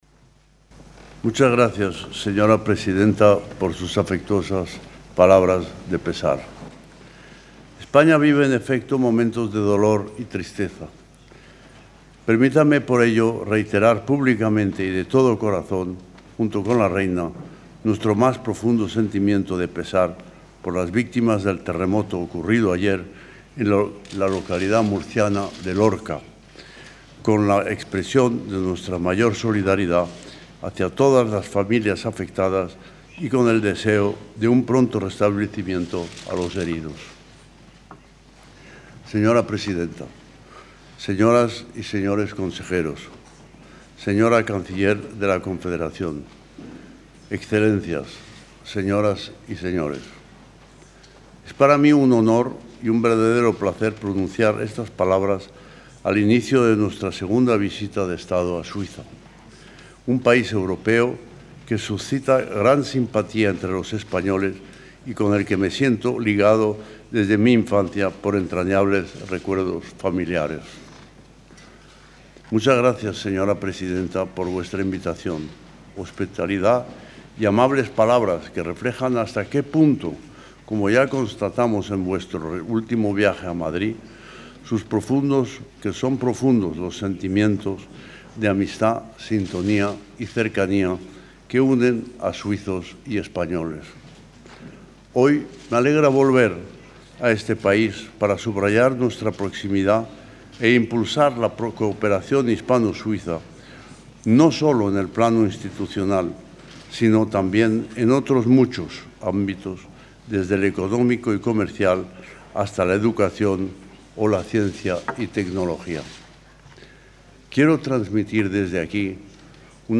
Discurso completo del rey de España, Juan Carlos I, en el Palacio Federal en Berna.